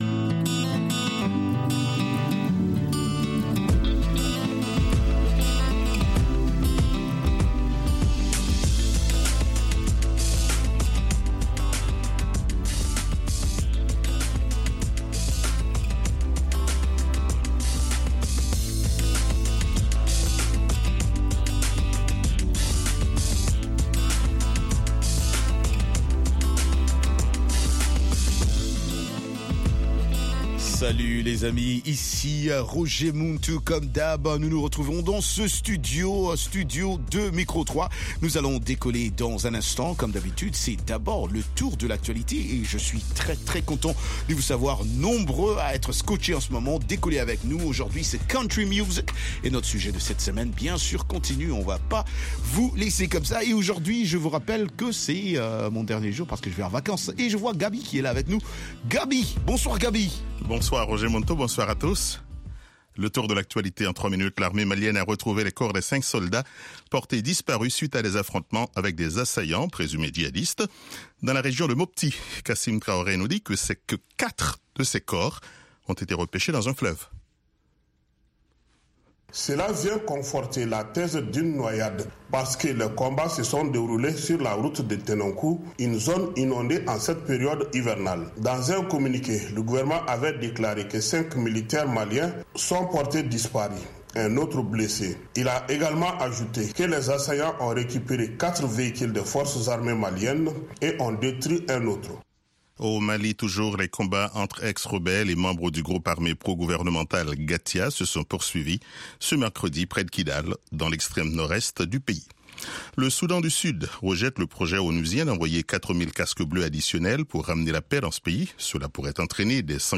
RM Show -Musique internationale & comedie